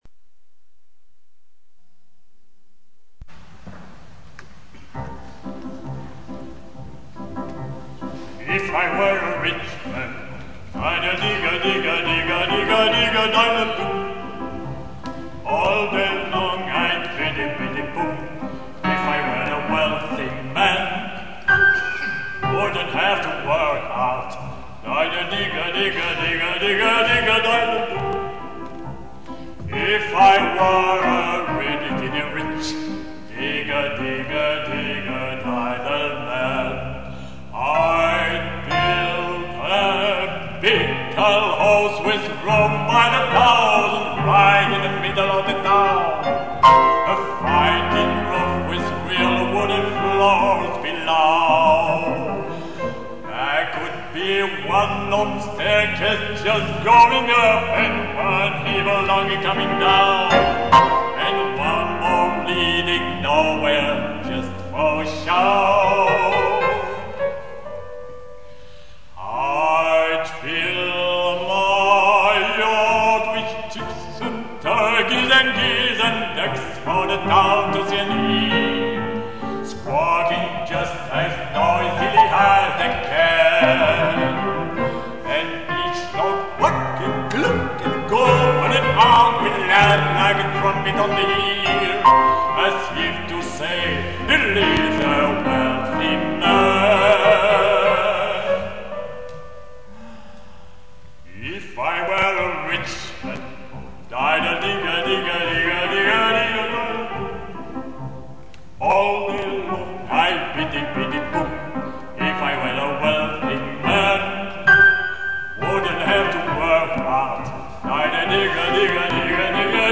baryton
au piano